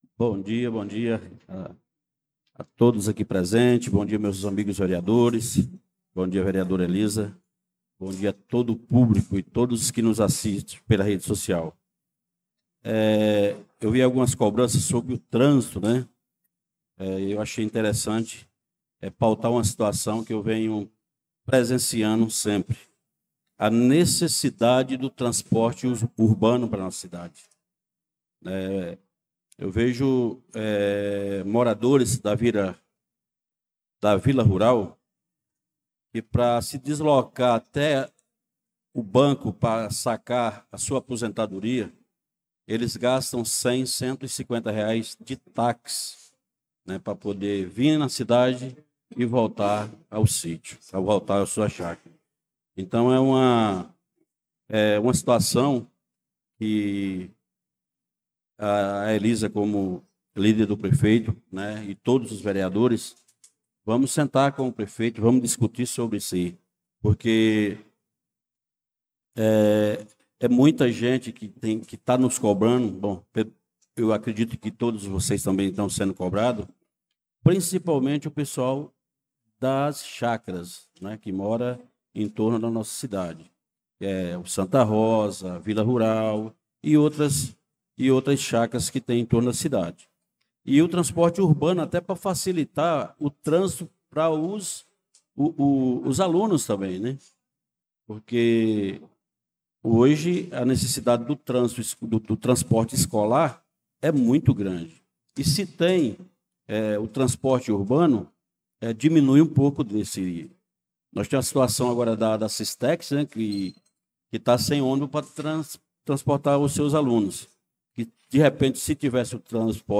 Pronunciamento do vereador Francisco Ailton na Sessão Ordinária do dia 11/02/2025